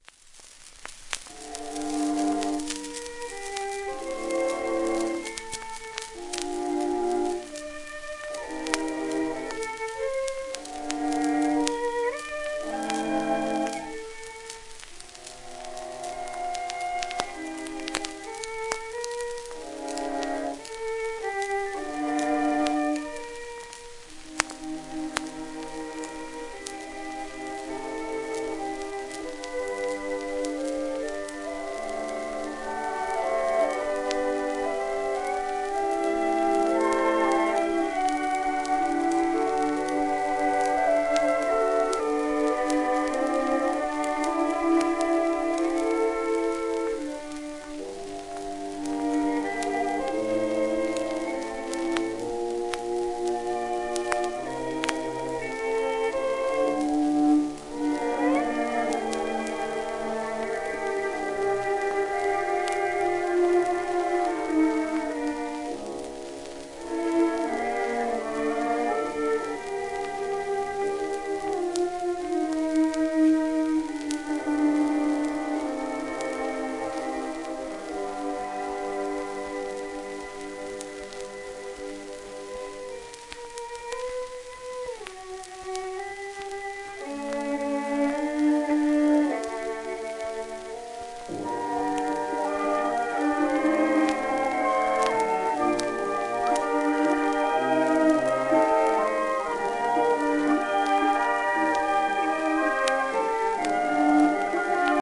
1920&1921年録音